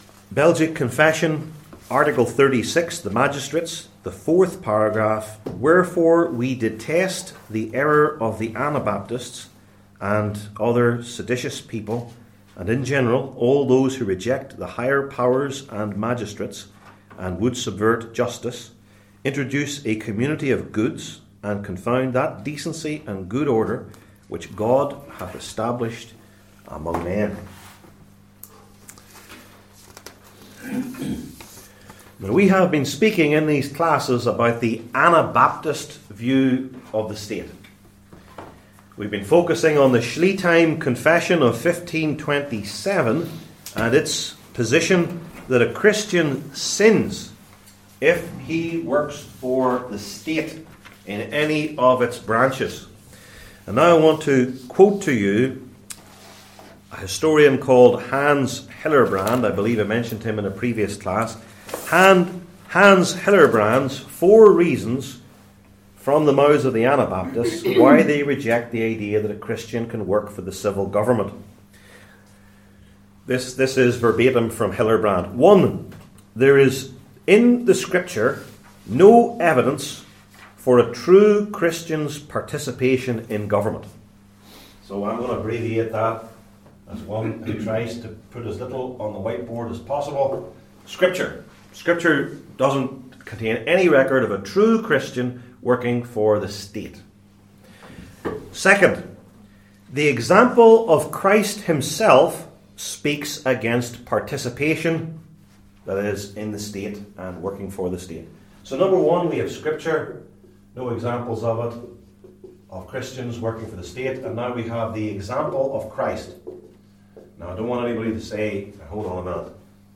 Passage: I Corinthians 1:26-31 Service Type: Belgic Confession Classes